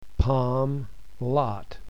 American English
palmlotGA.mp3